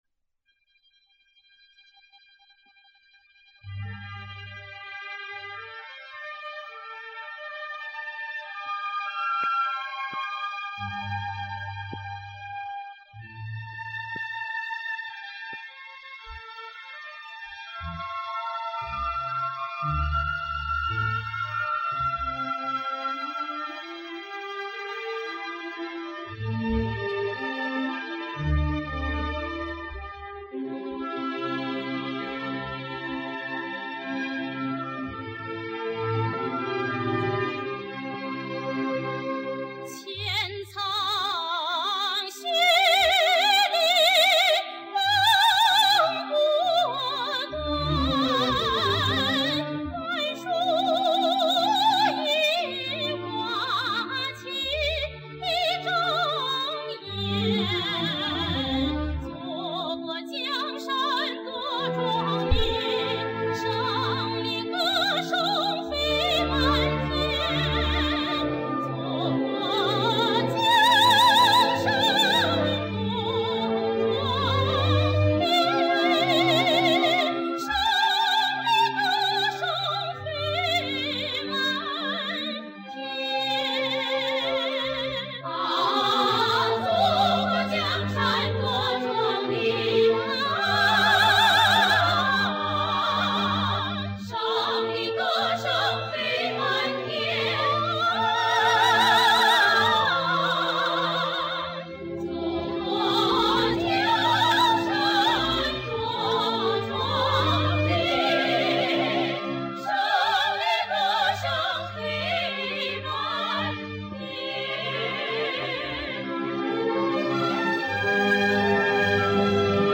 舞蹈音乐